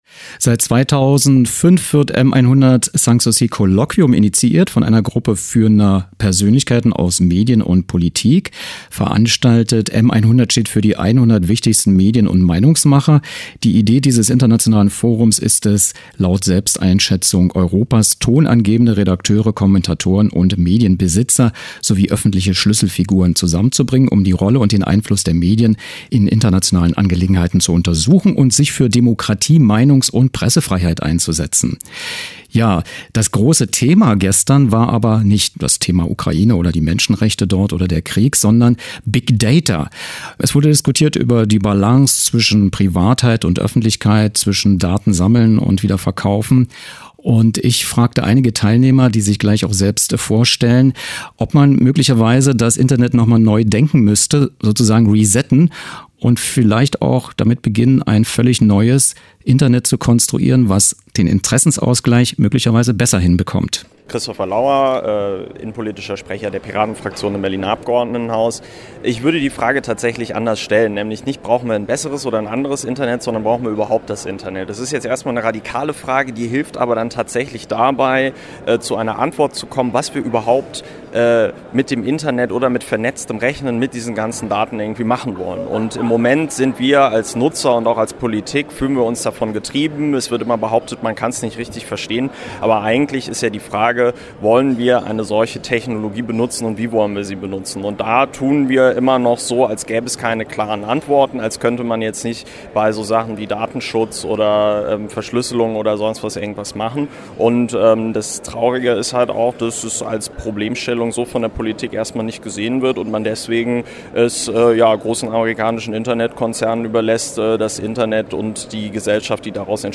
Was: Interview
Wer: Christopher Lauer, innenpolitischer Sprecher der Piraten-Fraktion im Berliner Abgeordnetenhaus
Wo: M100 Sanssouci Colloquium, Orangerie Sanssouci, Potsdam